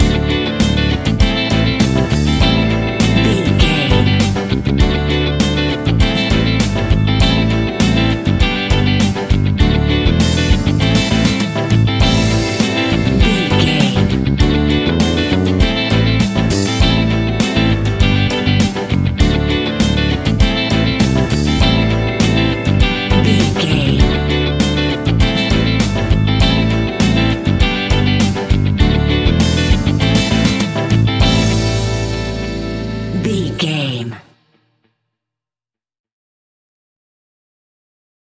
Uplifting
Aeolian/Minor
pop rock
indie pop
fun
energetic
motivational
acoustic guitars
drums
bass guitar
electric guitar
piano
organ